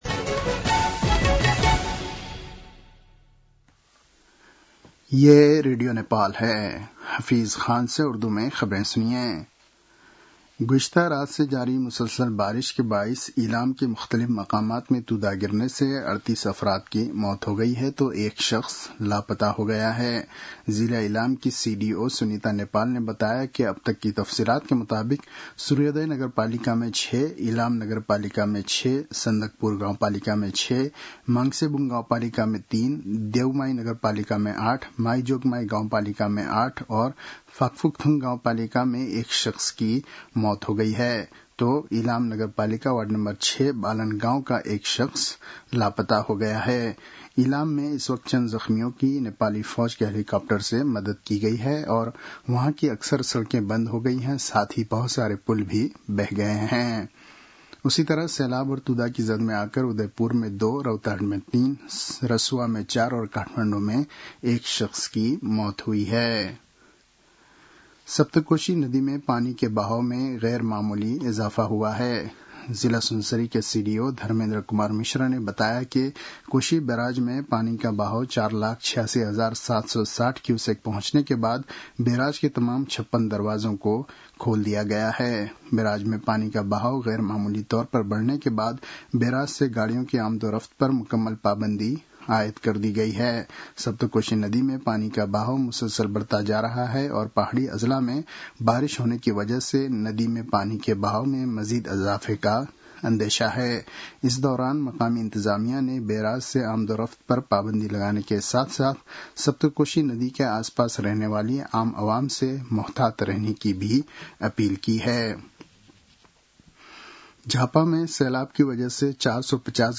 उर्दु भाषामा समाचार : १९ असोज , २०८२
Urdu-news-6-19.mp3